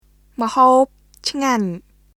[ムホープ・チガニュ　mhoːp cʰŋaɲ]